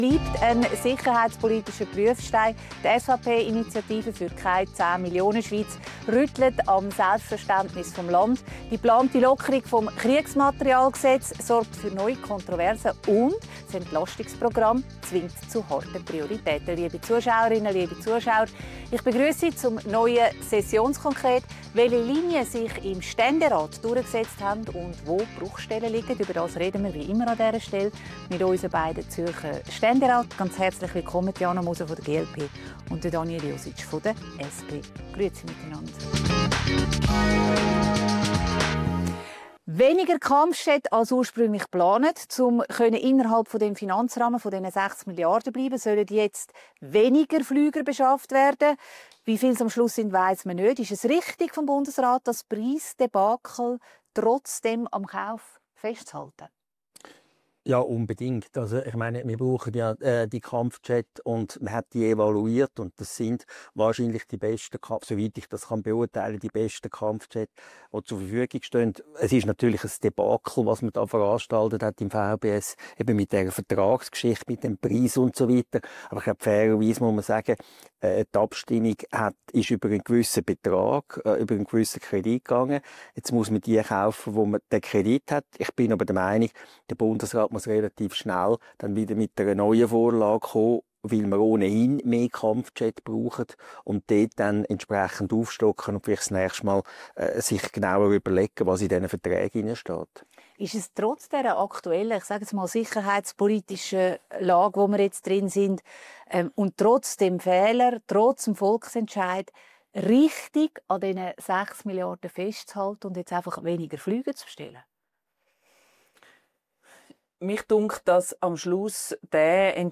diskutiert mit den beiden Zürcher Ständeräten Tiana Moser, GLP und Daniel Jositsch, SP